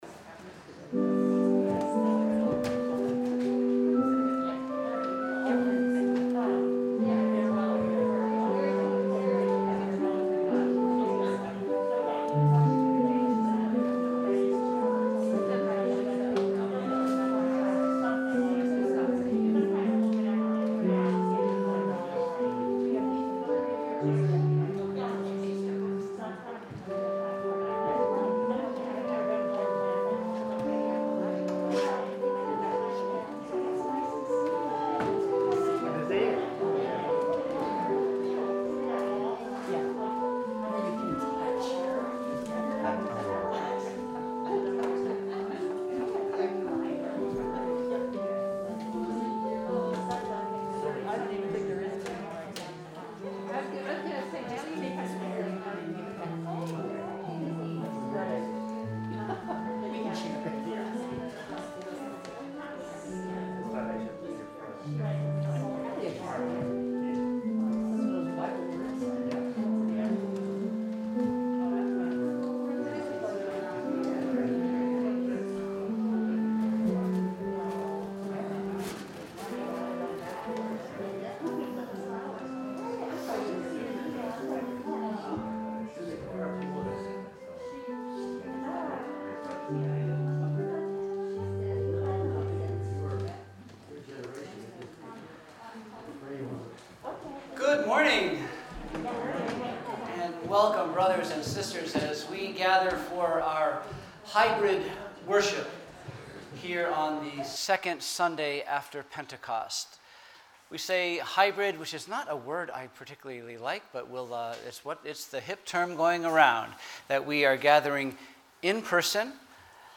We held worship on Sunday, June 6, 2021 at 10am!